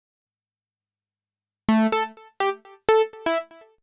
XTRA023_VOCAL_125_A_SC3.wav